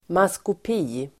Ladda ner uttalet
maskopi substantiv, collusion Uttal: [maskop'i:] Böjningar: maskopien, maskopier Synonymer: komplott Definition: hemligt samförstånd Exempel: stå el. vara i maskopi med någon (be in collusion with sby)